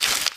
High Quality Footsteps
STEPS Newspaper, Walk 10.wav